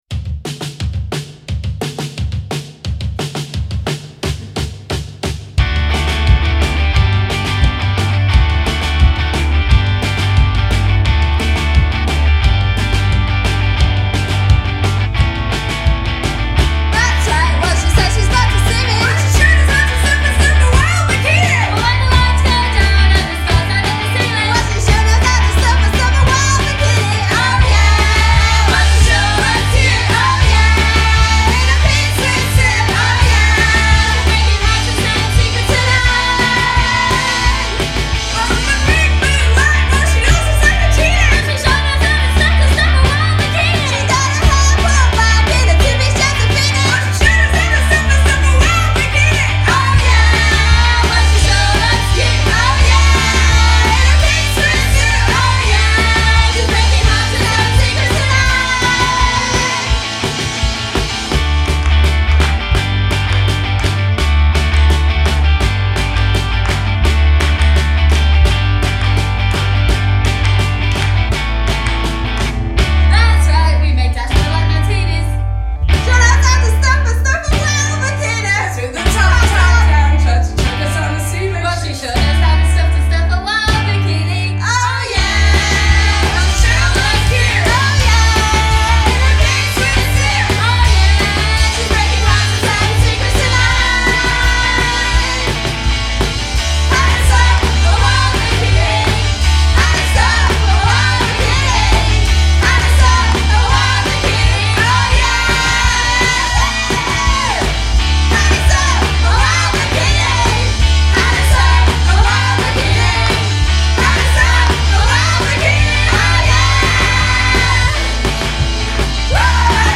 trio femminile da Manchester